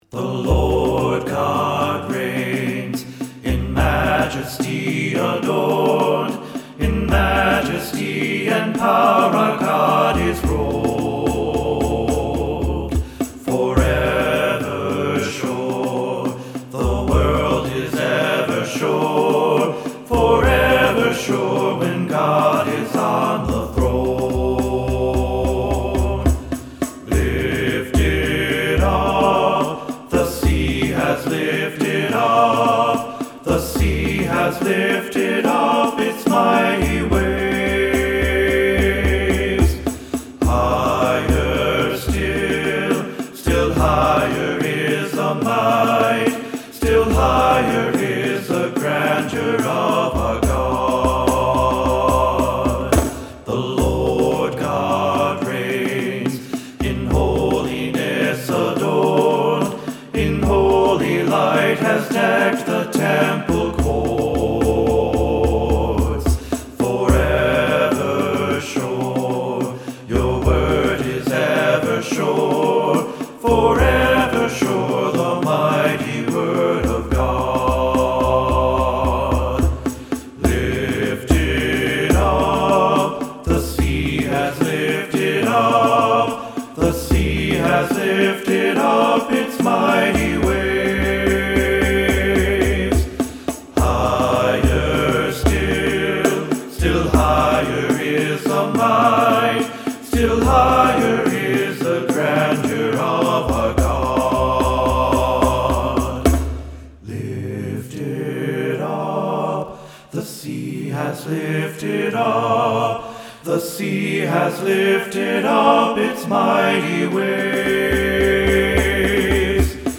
This hymn is a free download.